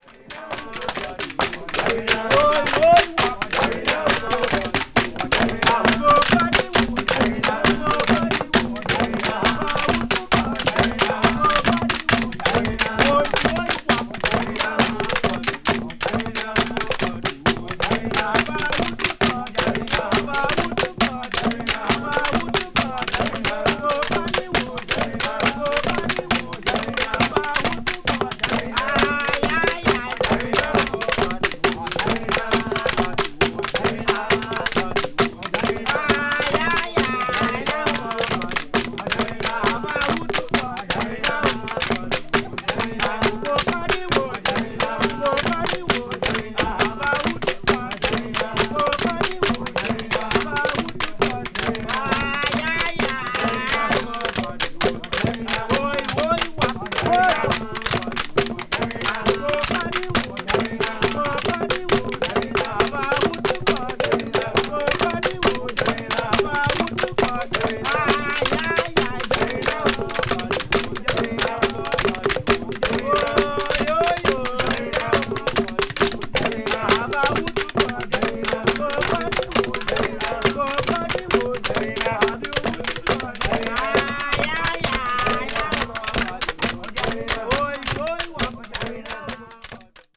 media : VG+/VG+(light surface noises and click noises caused by slightly wear and hairlines.)
It features field recordings made in the Caribbean island nation of Trinidad.
This is a valuable document showcasing traditional percussive music from the region.
afro-caribbean   ethnic music   field recording   primitive   trinidad   world music